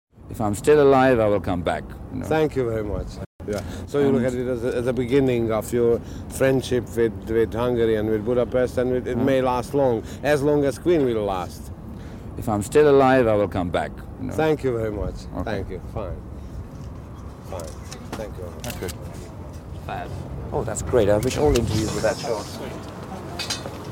– Mp3 Sound Effect "If I'm still alive..." – Freddie Mercury interview during last tour with Queen, 1986. On 27 July 1986, Queen performed live at the Népstadion in the Hungarian capital of Budapest.
Later, Mercury was asked by a Hungarian TV journalist whether the concert represented the start of an enduring friendship between Queen and Hungary.